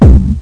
tr-909-mid-plate.mp3